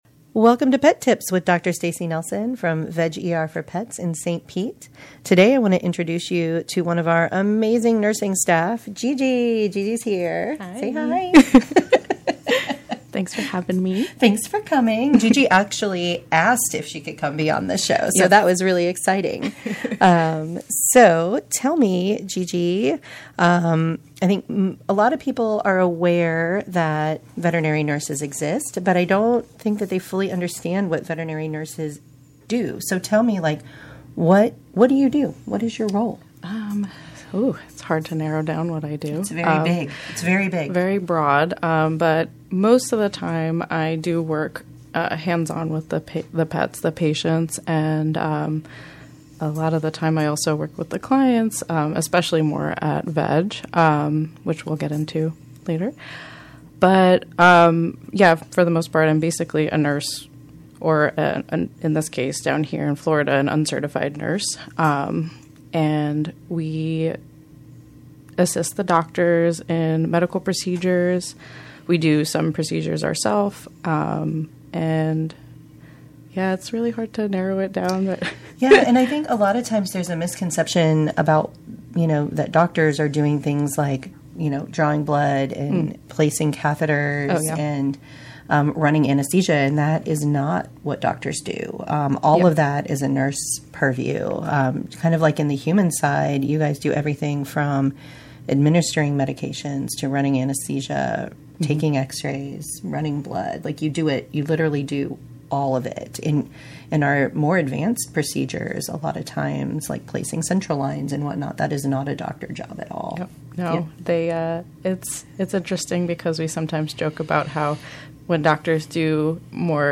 interviews a vet nurse